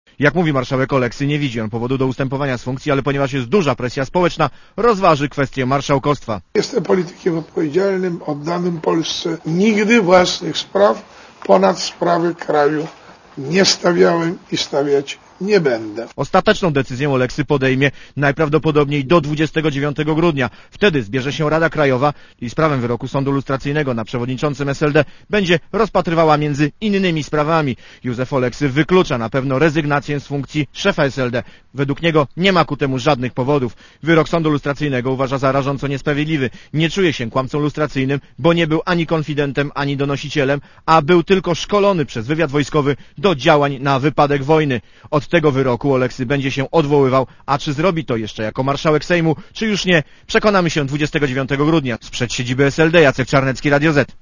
Relacja reportera Radia ZET Oceń jakość naszego artykułu: Twoja opinia pozwala nam tworzyć lepsze treści.
oleksykonferencja.mp3